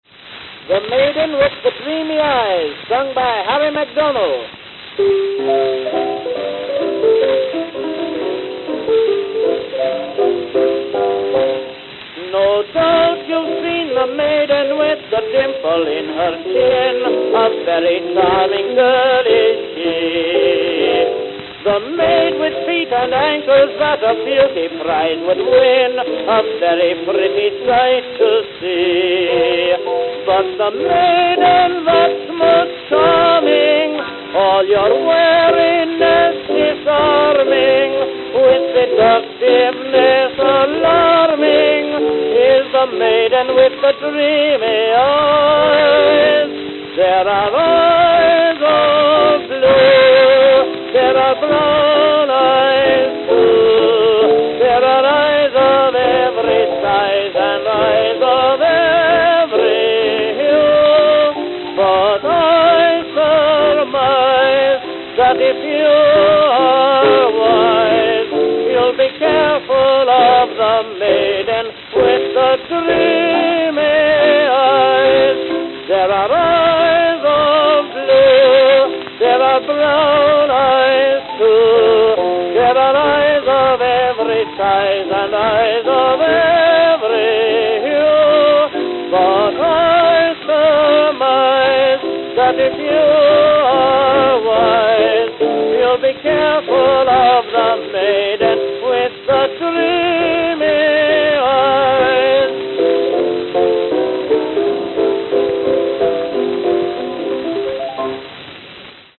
Philadelphia, Pennsylvania (?)